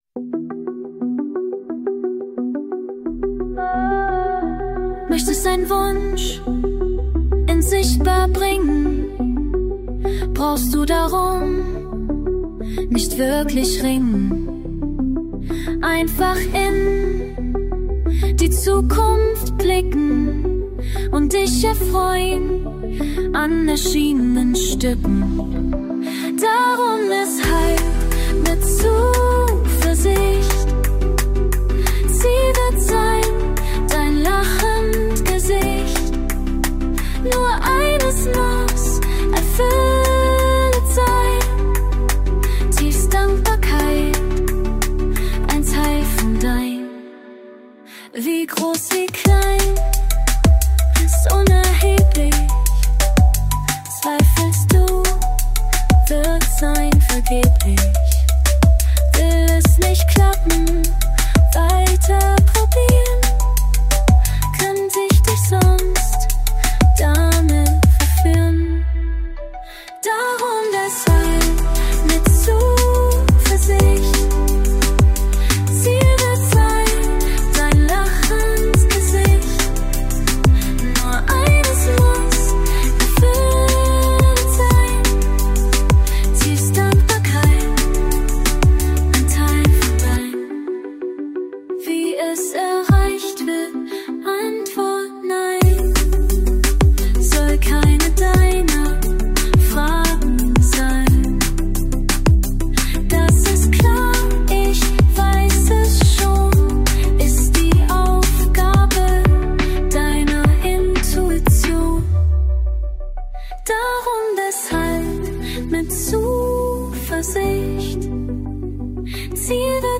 Liquid DNB